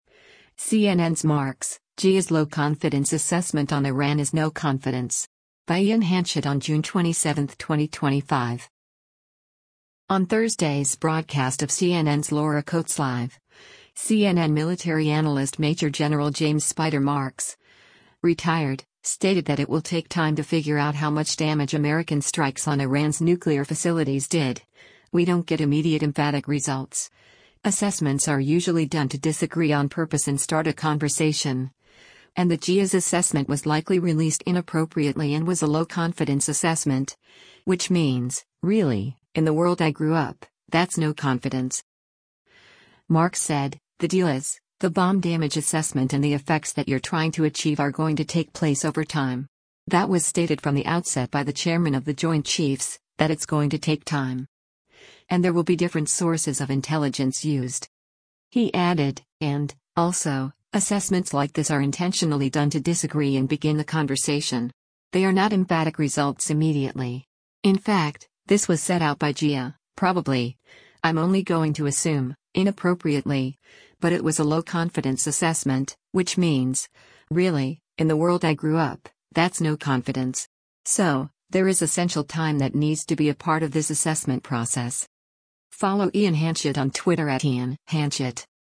On Thursday’s broadcast of CNN’s “Laura Coates Live,” CNN Military Analyst Major Gen. James “Spider” Marks (Ret.) stated that it will take time to figure out how much damage American strikes on Iran’s nuclear facilities did, we don’t get immediate emphatic results, assessments are usually done to disagree on purpose and start a conversation, and the DIA’s assessment was likely released inappropriately and “was a low-confidence assessment, which means, really, in the world I grew up, that’s no confidence.”